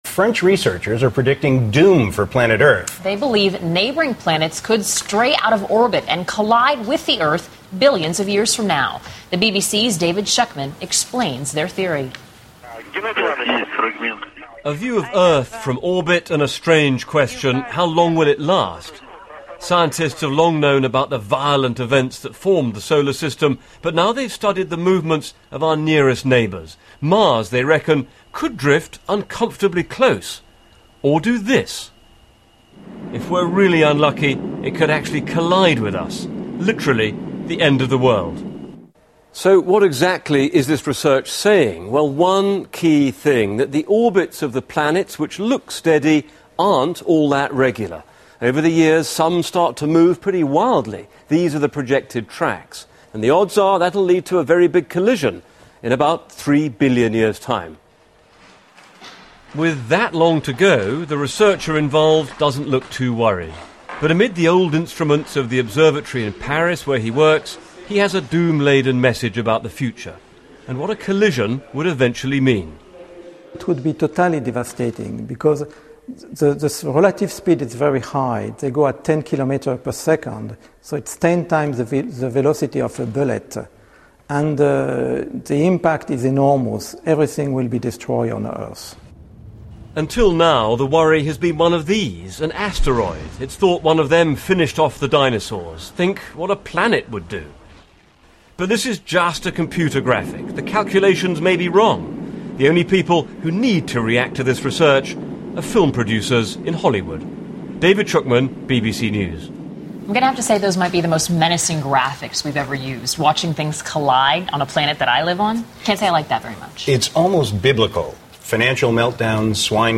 News
They believe neighboring planets could stray out of orbit and collide with the Earth billions of years from now. The BBC's David Shukman explains their theory.